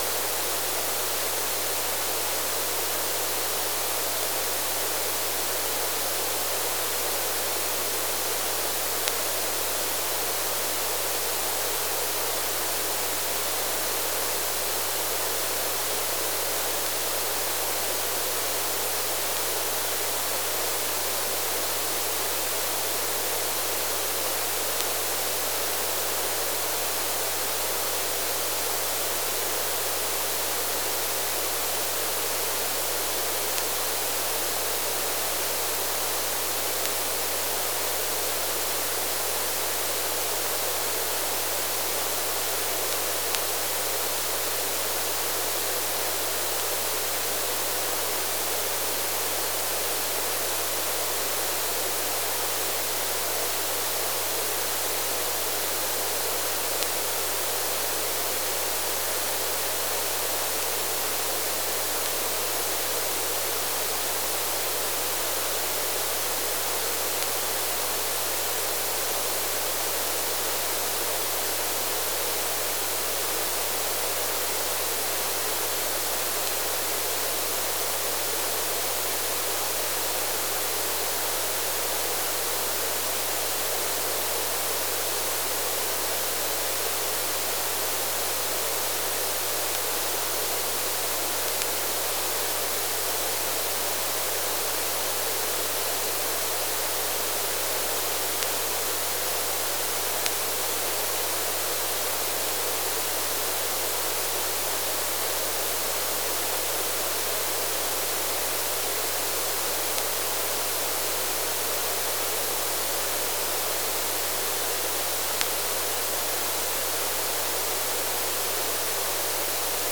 Analisi dati rivelatore beta acquisito come file audio